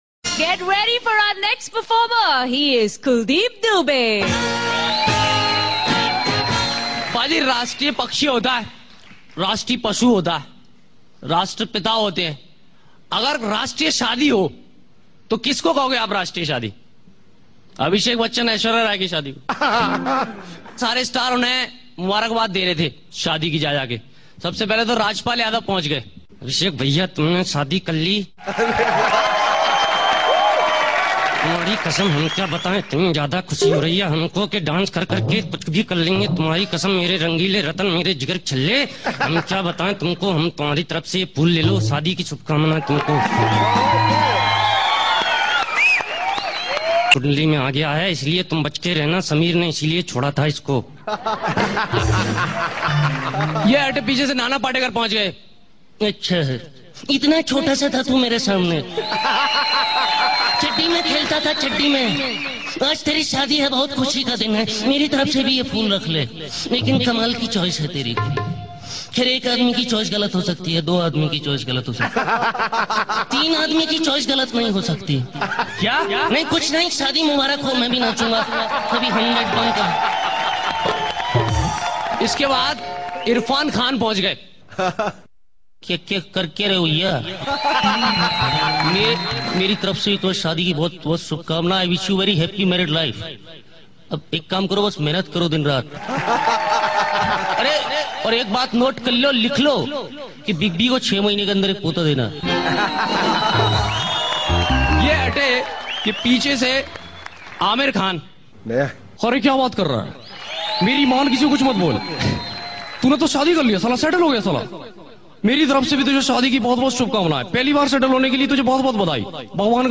HomeMp3 Audio Songs > Whatsapp Audios > Funny Mimicry Voice